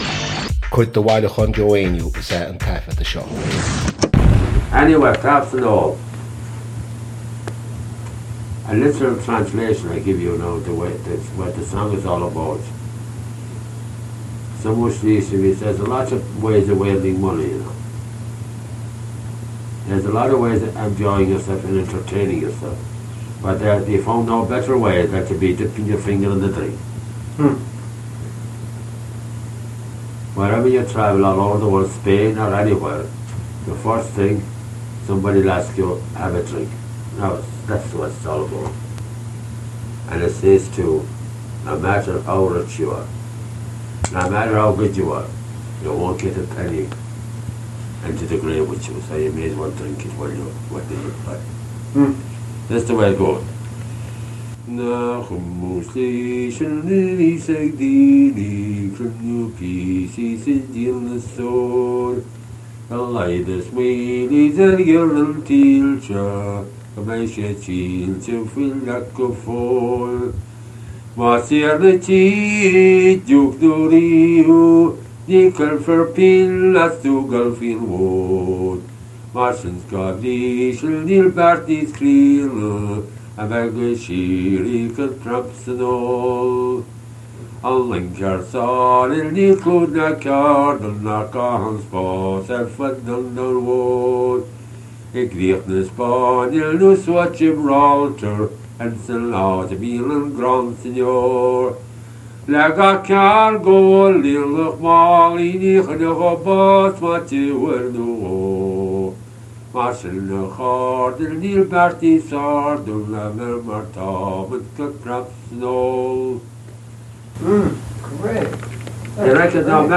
• Catagóir (Category): song.
• Ainm an té a thug (Name of Informant): Joe Heaney.
• Suíomh an taifeadta (Recording Location): Wesleyan University, Middletown, Connecticut, United States of America.
This lively drinking song was composed by Mayo poet Riocárd Bairéad (c. 1740-1819); for text and discussion see Nicholas Williams, Riocárd Bairéad: Amhráin (Dublin, 1978), 72 and notes.